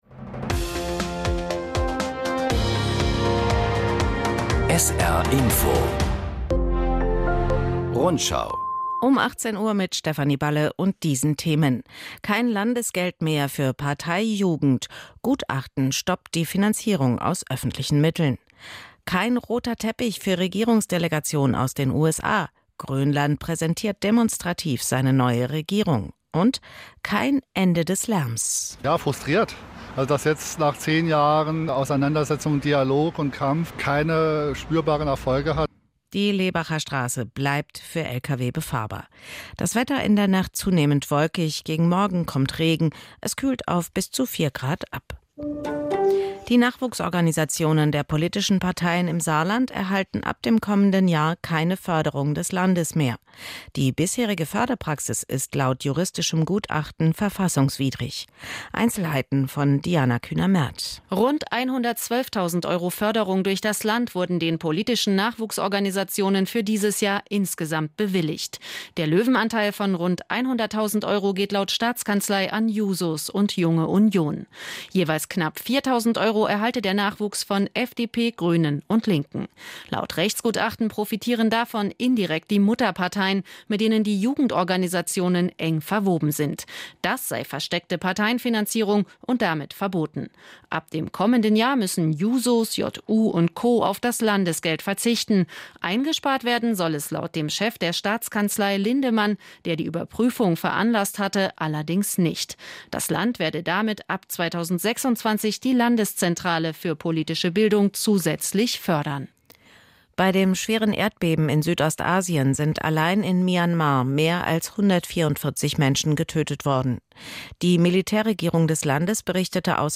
… continue reading 3 эпизода # Nachrichten